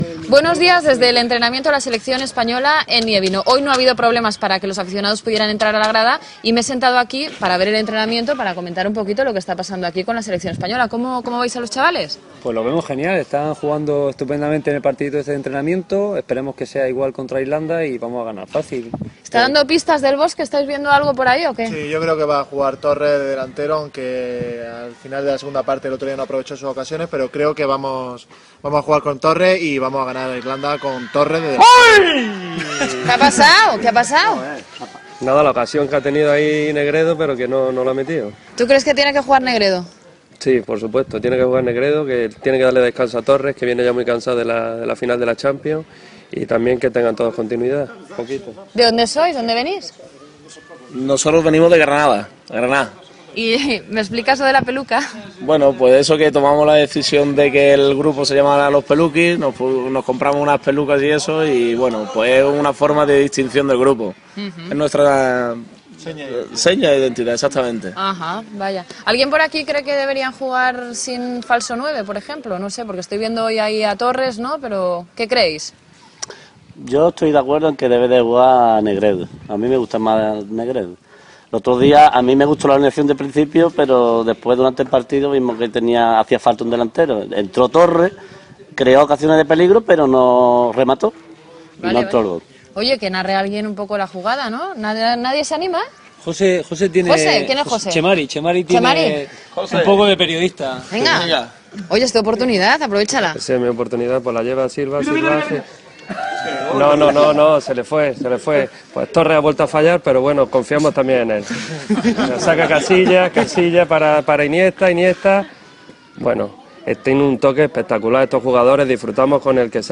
Entrevista als aficionats espanyols que veuen l'entrenament de la selecció masculina de futbol professional, a Gniewino (Polònia), abans del seu enfrontament amb la selecció d'Irlanda a l'Eurocopa celebrada a Polònia i Ucraïna
Esportiu